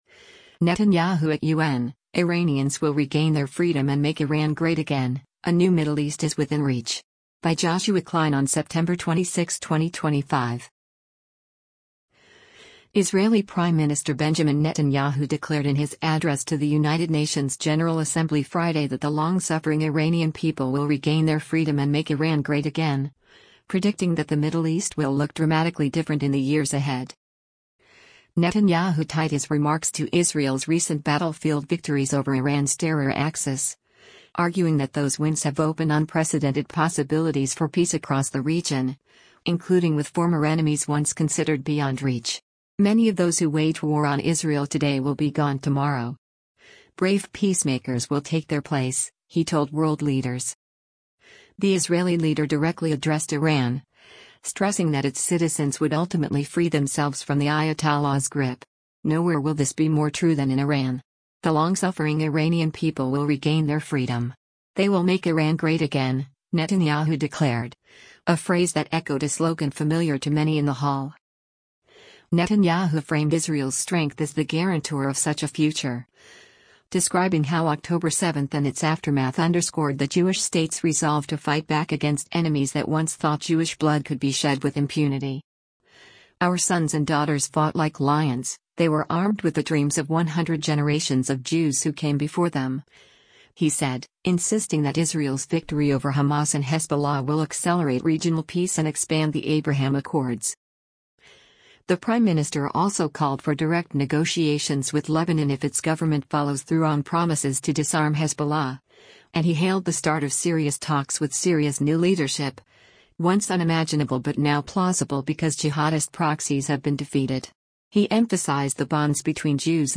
Prime Minister of Israel Benjamin Netanyahu speaks during the 80th session of the UN’s G